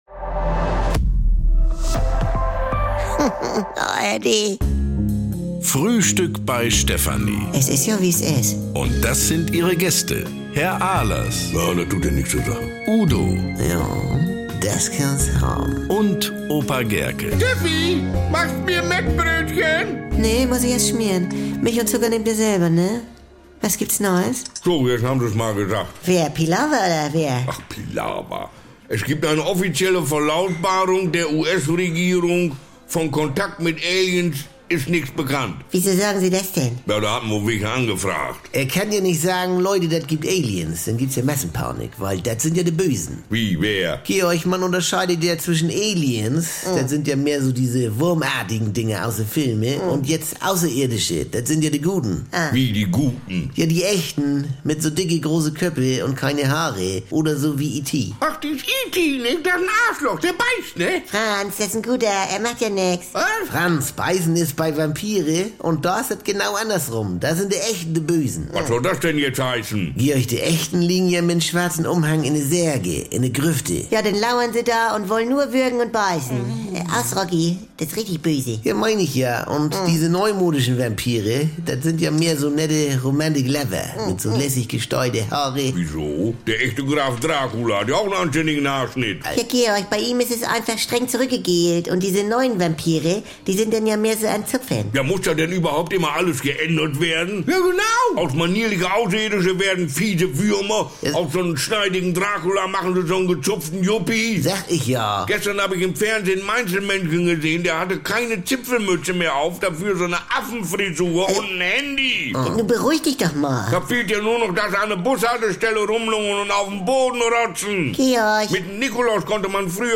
Garantiert norddeutsch mit trockenen Kommentaren, deftigem Humor und leckeren Missverständnissen.